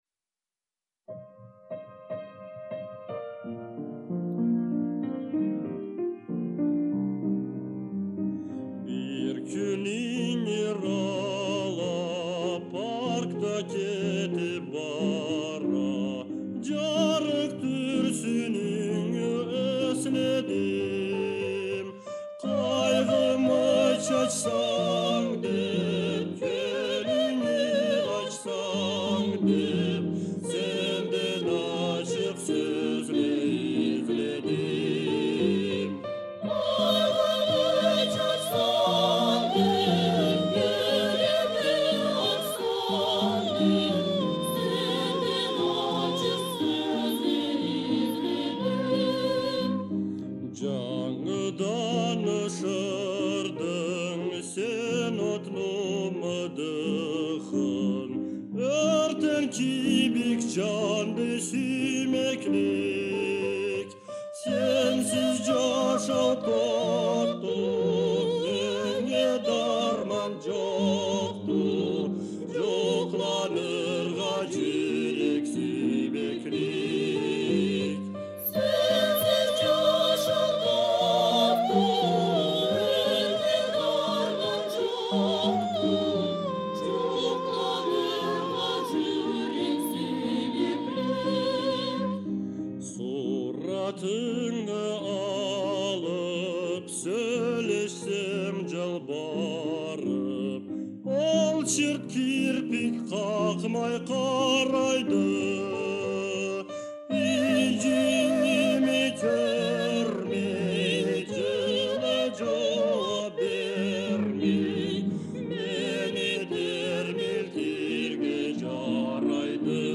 жен. группа хора радио